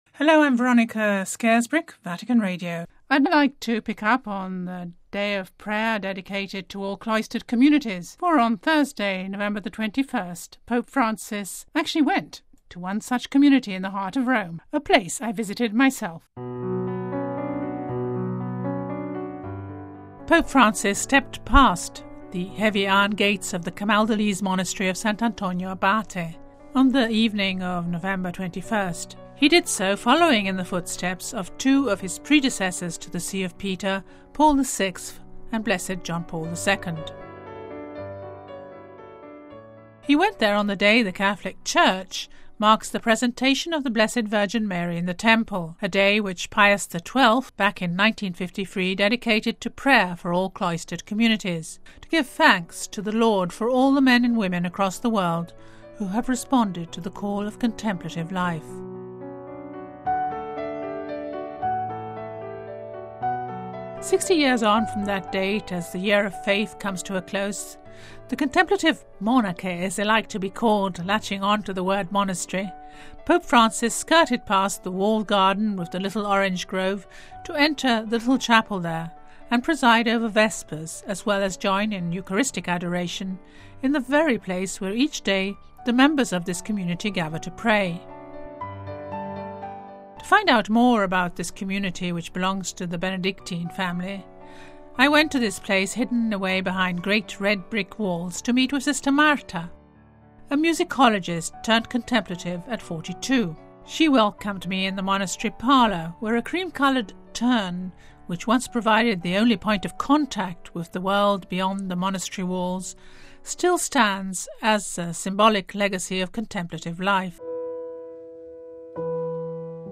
(Vatican Radio) When Pope Francis stepped past the heavy iron gates of the Camaldolese Monastery of Sant’Antonio Abate at the foot of Rome's Aventine hill on the evening of Thursday November 21st, he did so following in the footsteps of two of his predecessors to the See of Peter : Paul VI and Blessed John Paul II.